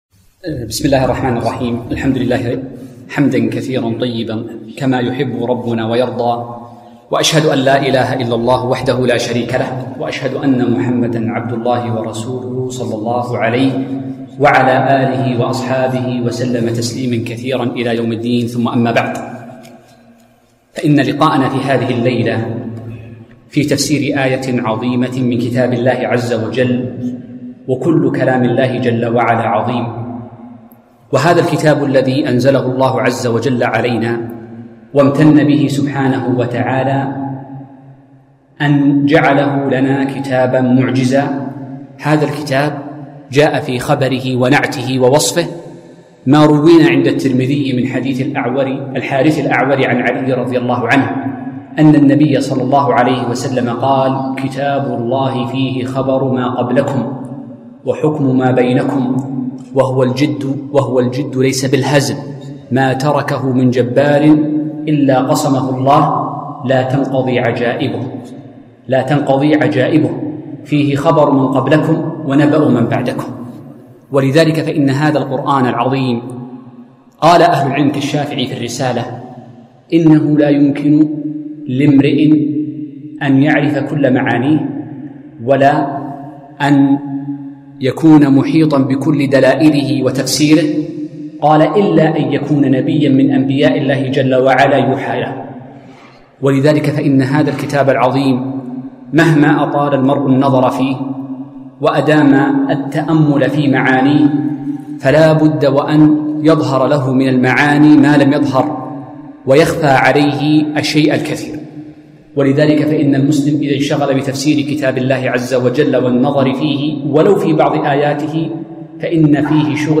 محاضرة - وما آتاكم الرسول فخذوه وما نهاكم عنه فانتهوا